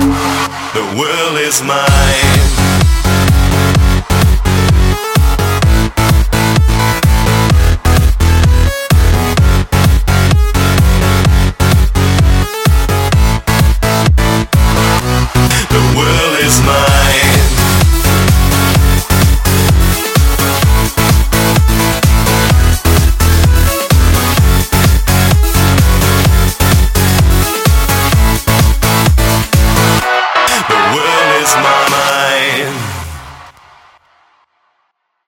Из клубной музыки